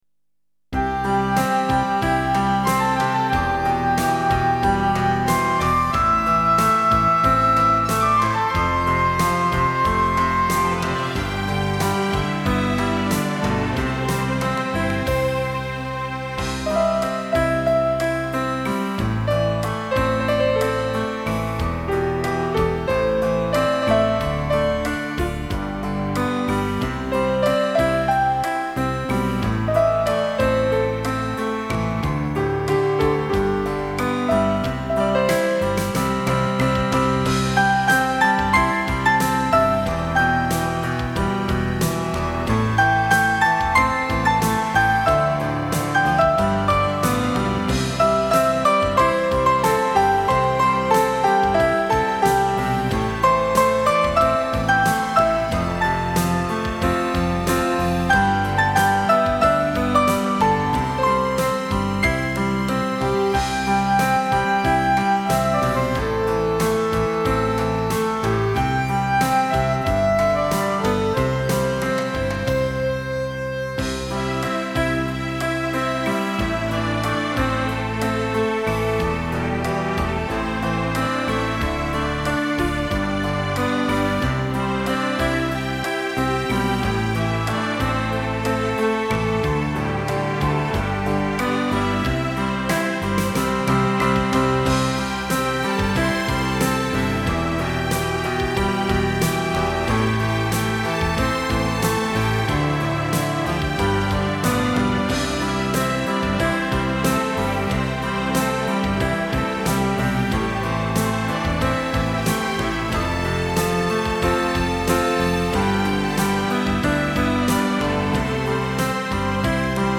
纯音